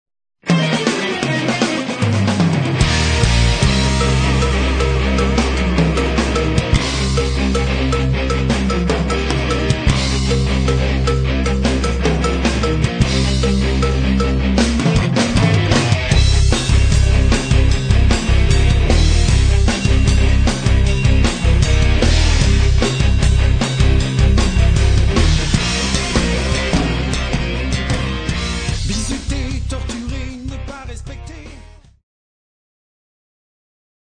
Groupe Rock PAU